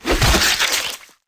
Melee Weapon Attack 32.wav